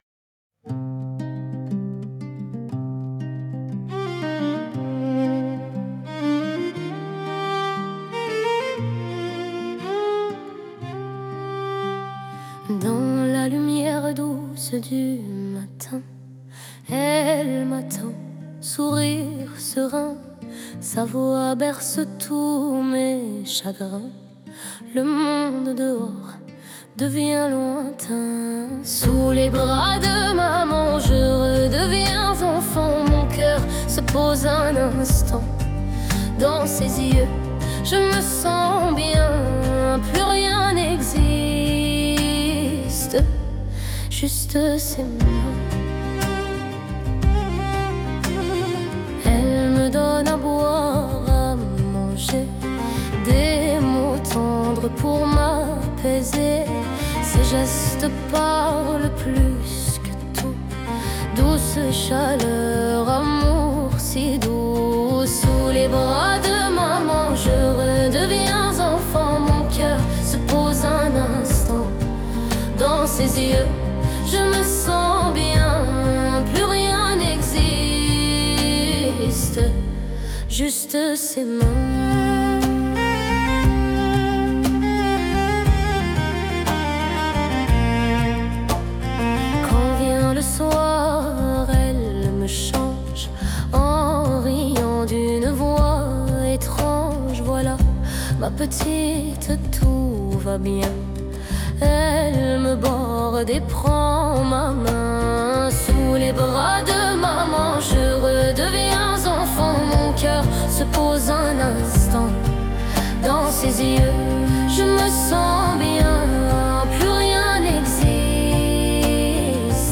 Ich wollte eine Atmosphäre schaffen, die sanft, liebevoll und beschützend wirkt, ohne irgendeinen sexuellen Bezug.